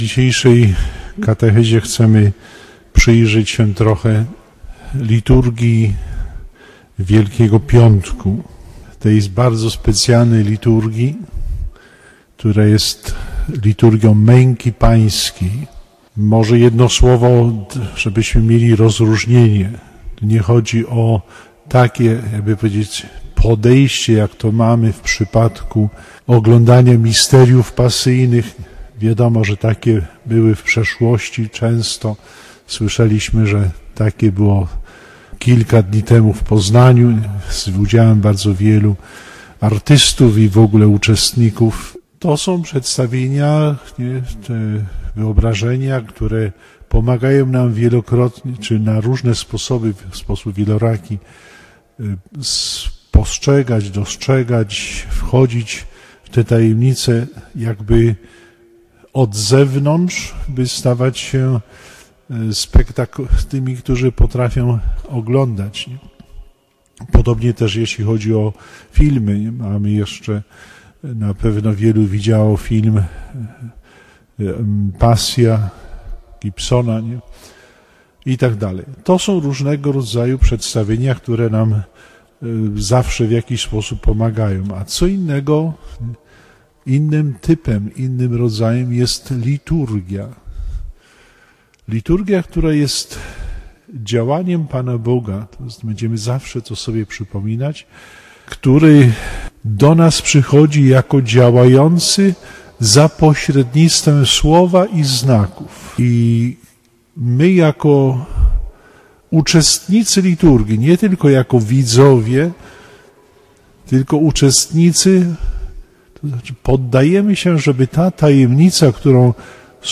Dalszy ciąg katechez biskupa Zbigniewa Kiernikowskiego, które wprowadzają w czas Triduum Paschalnego.